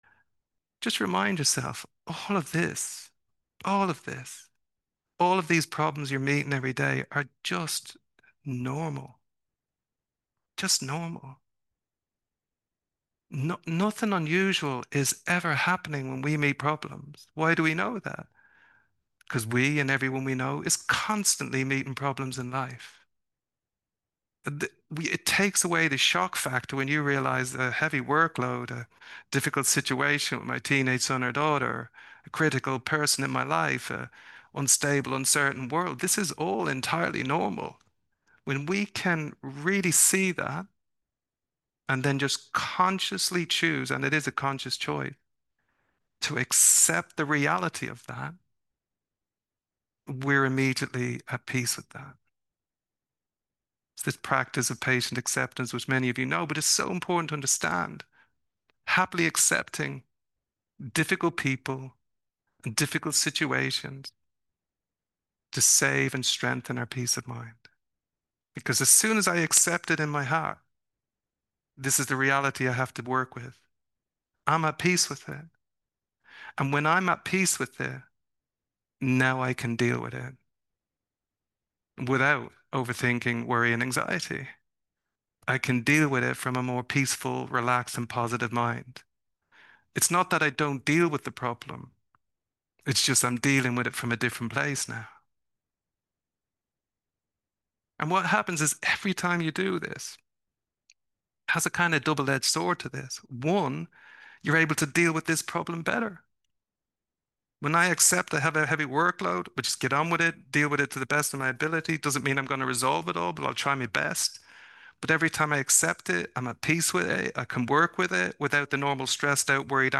teaching extract